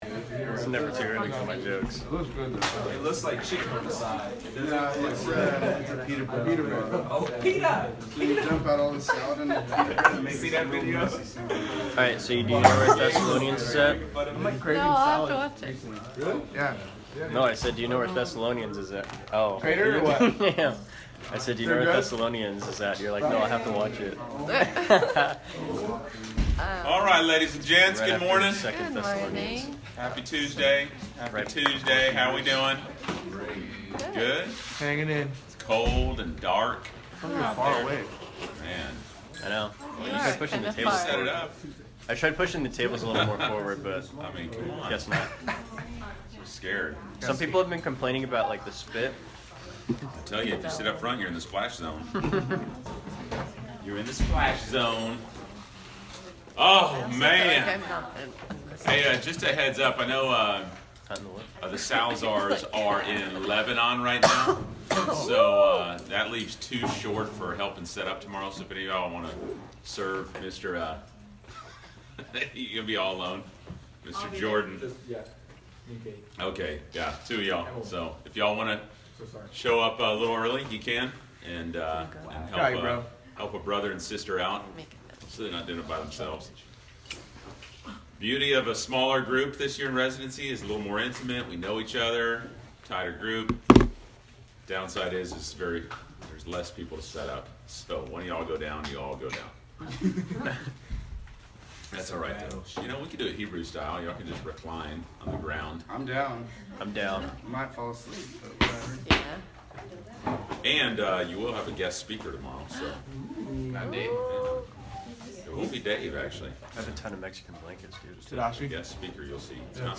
Class Session Audio November 29